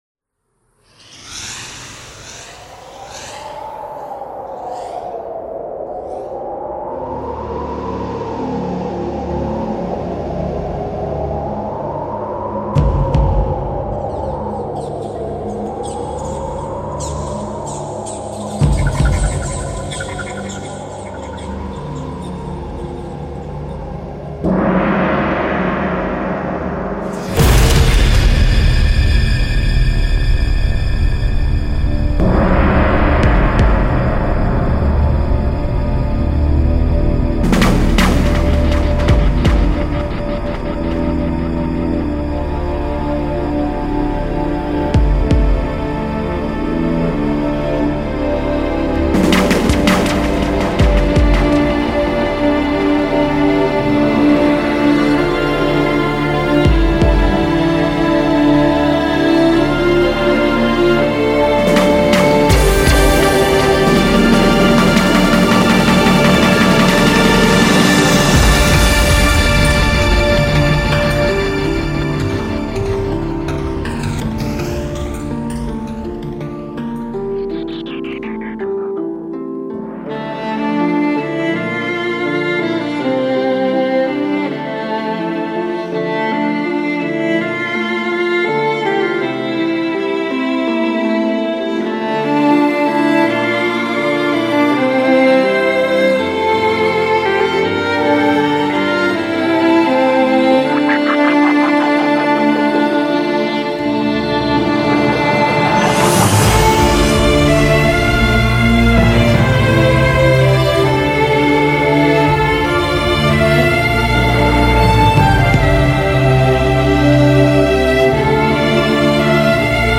نوع آهنگ: لایت]